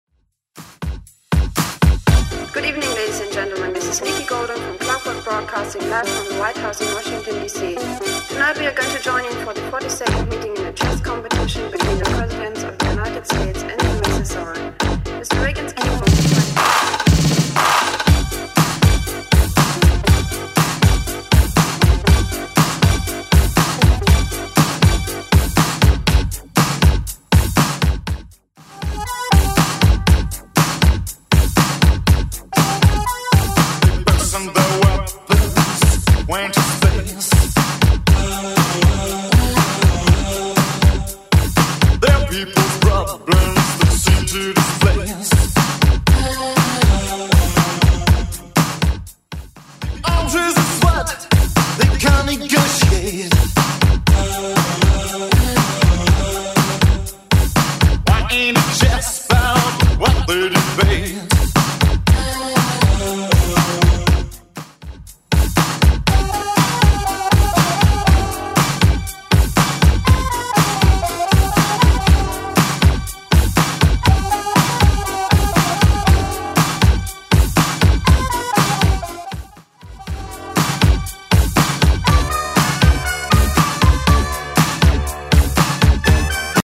Genre: TOP40
BPM: 85